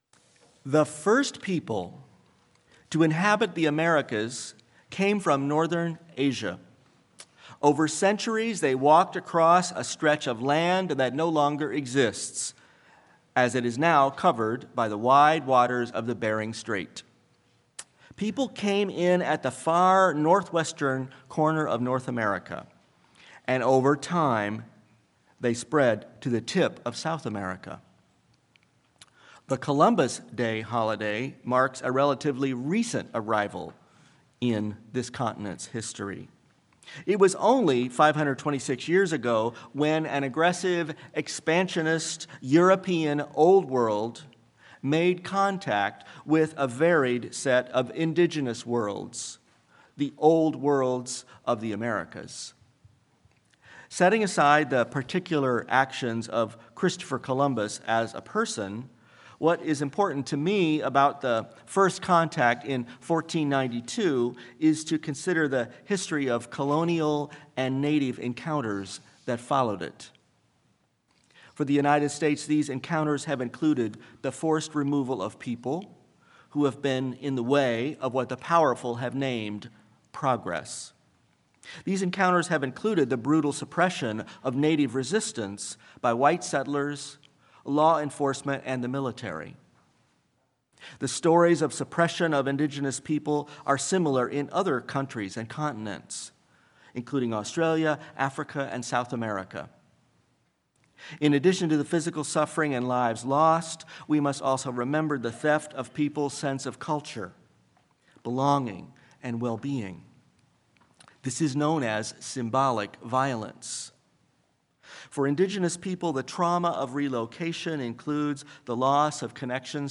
Sermon-This-Land.mp3